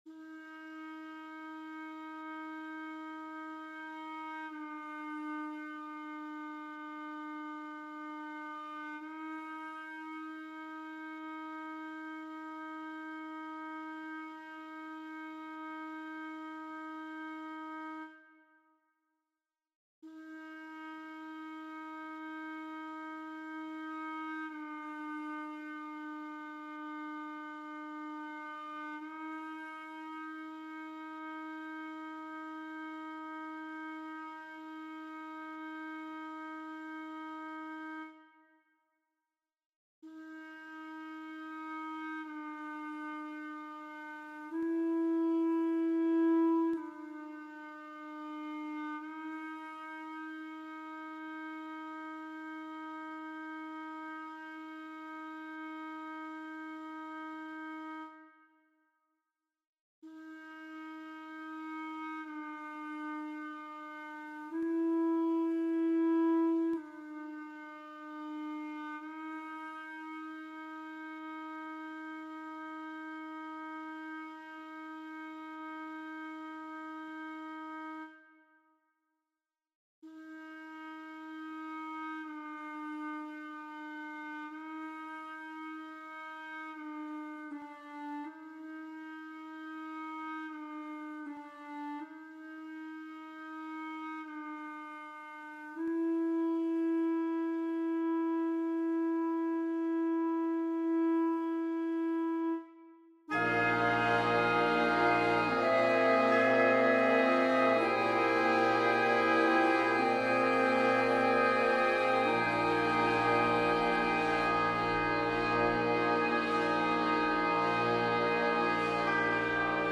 Prose Poem #36 for Wind Quintet in B-flat ("Lonian"/Lydian b7+3)